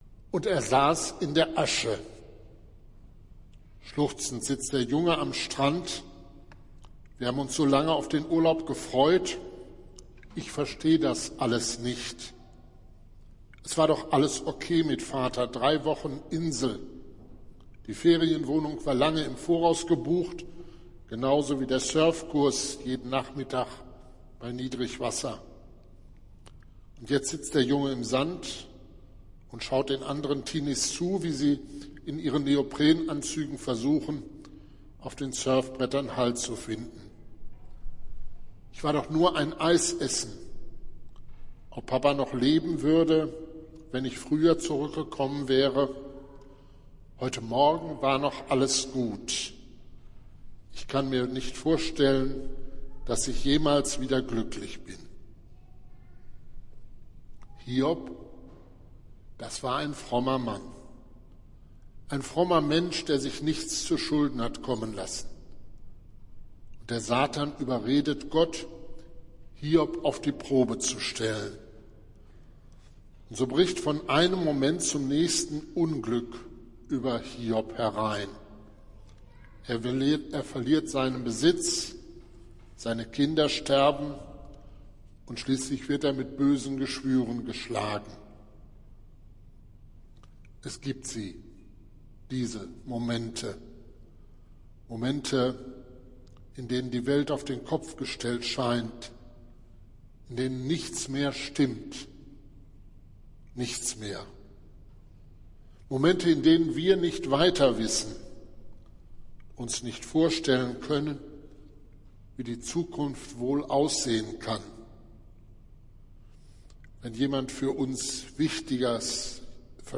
Predigt des Gottesdienstes aus der Zionskirche vom Sonntag, 26. Februar 2023